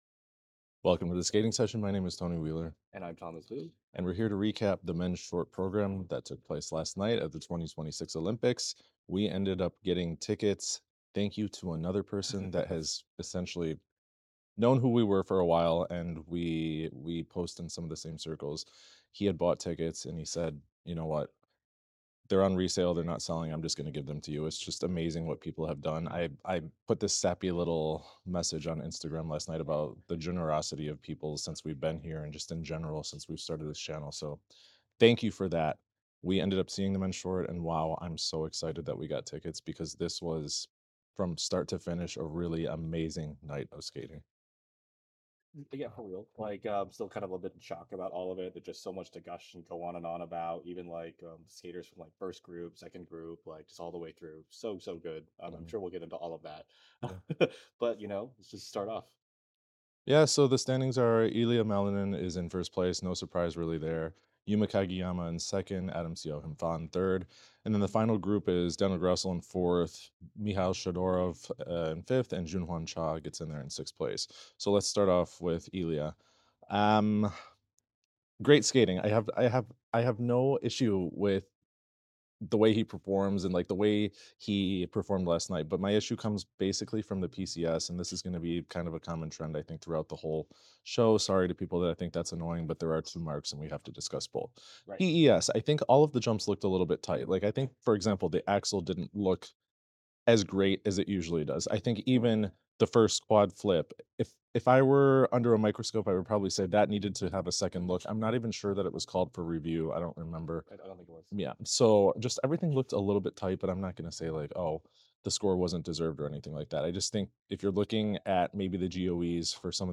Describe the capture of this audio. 2026 OLYMPICS MENS SHORT PROGRAM Review & Insight (Live from Milan!)